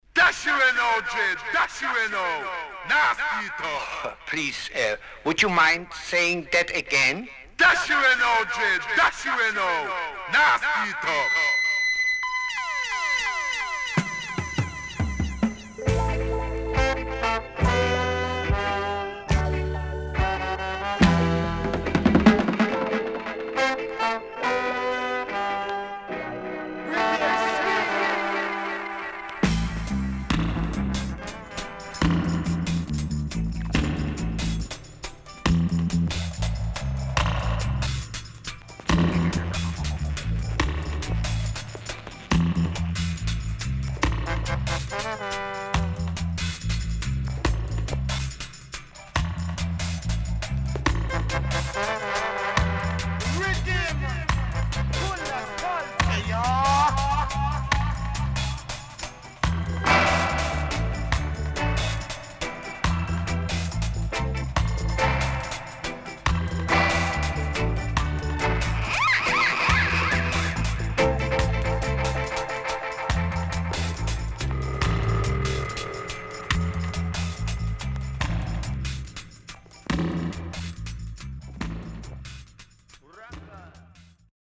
SIDE B:少しノイズ入りますが良好です。